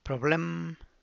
problem[proble’mm]